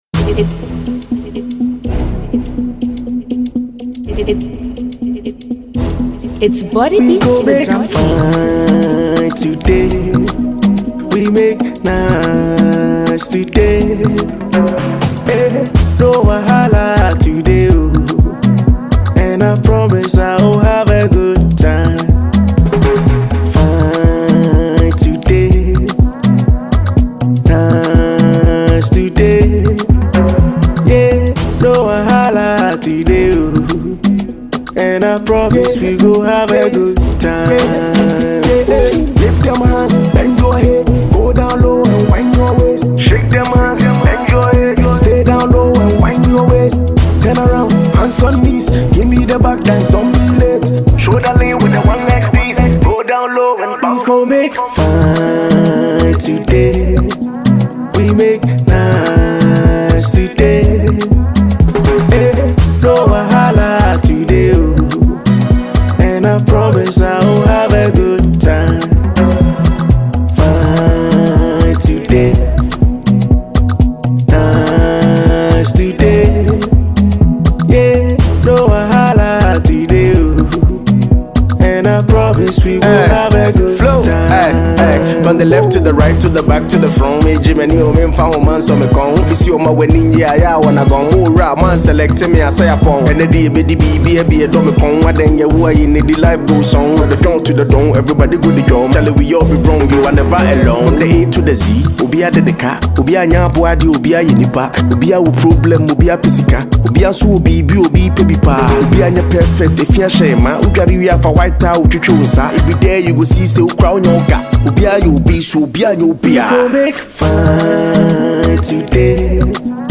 Ghanaian heavyweight rapper